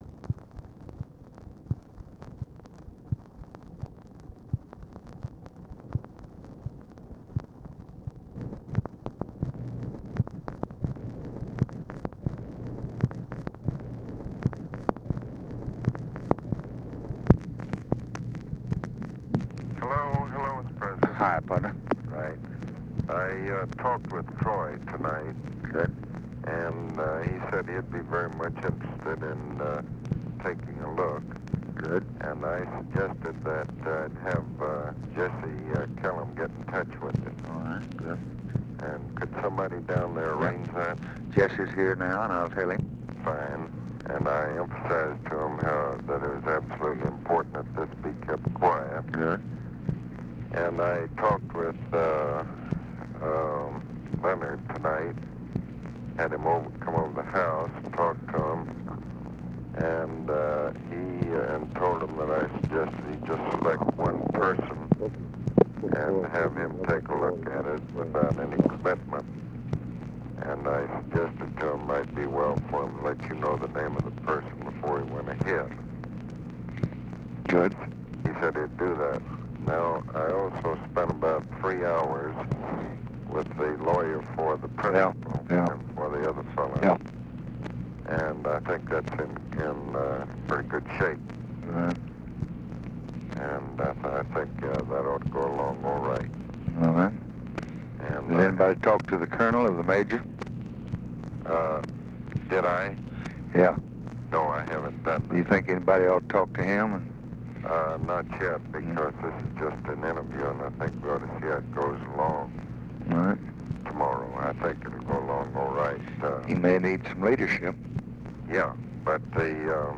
Conversation with ABE FORTAS, November 12, 1964
Secret White House Tapes | Lyndon B. Johnson Presidency